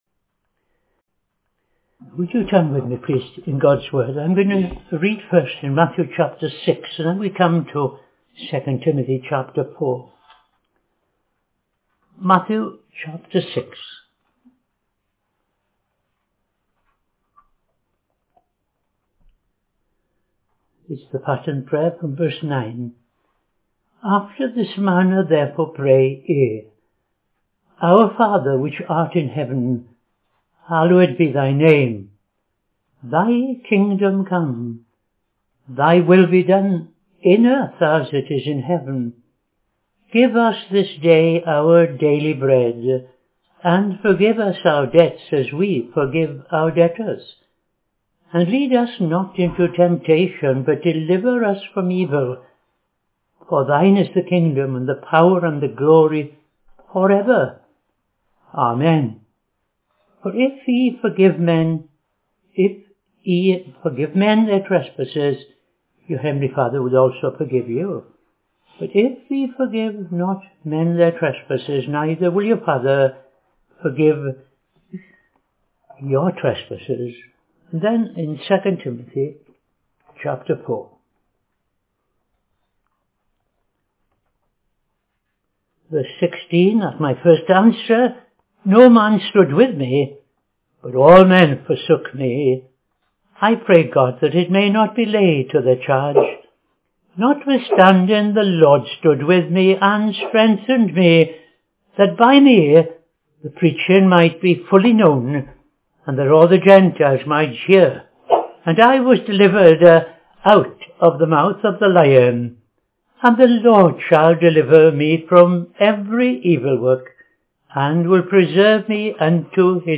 Reading Matthew 6:9-15; II Timothy 4:16-18